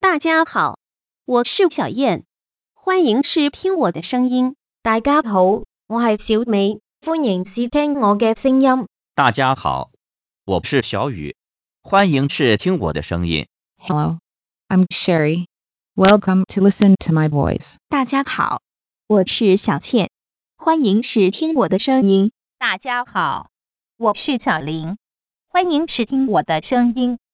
xf5发音人，免安装-绿化版，包含全部6个发音人（
小燕、小美、小宇、Sherri、小倩、小琳，点此可以试听发音效果） 。